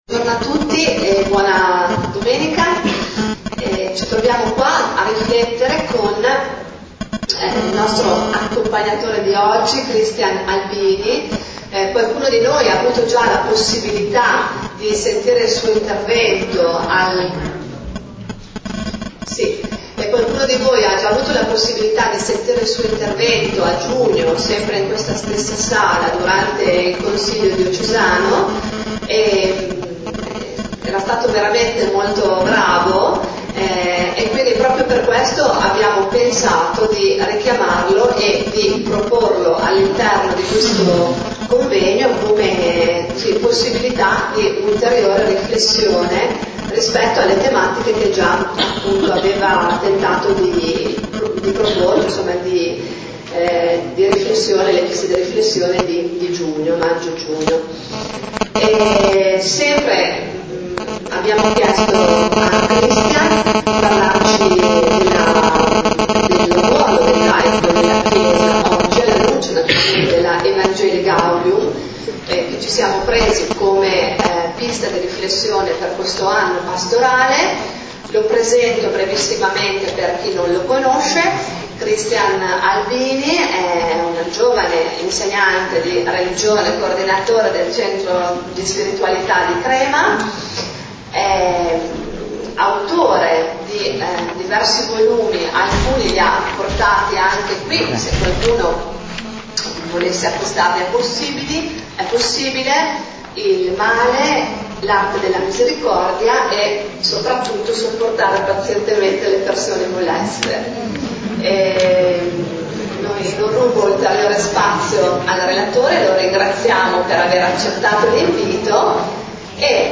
Registrazione audio dell’incontro a Fiorenzuola di domenica 9 ottobre.